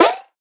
double_jump.ogg